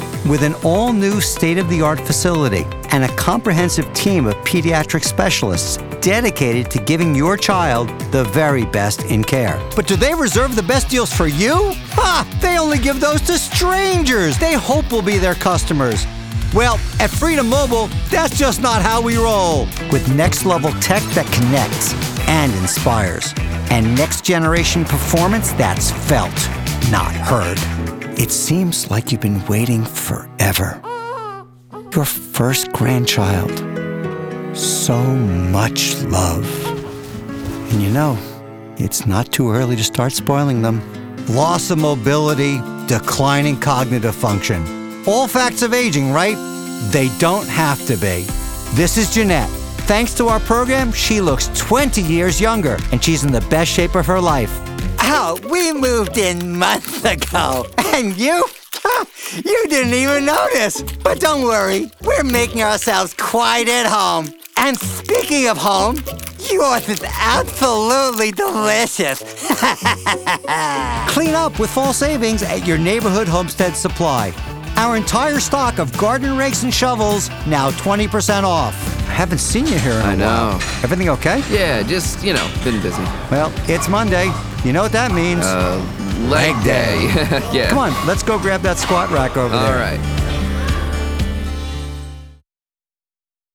Commercial Demo
Middle Aged